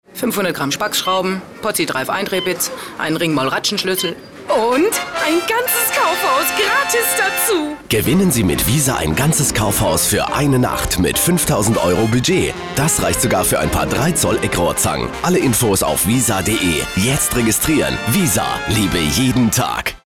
rauchige, knarzig-raue, sehr tief gehende Blues-Stimme, gut für Comic/Kunstfiguren (Lenya / Knef), englisch fliessend außergewöhnliches, mitreissendes Lachen, Kunststimmen und Imitation von Dialekten
Sprechprobe: Industrie (Muttersprache):
female German voice over artist, deep and rough voice preferably comics, video-games, strange characters. blues-singer. English fluent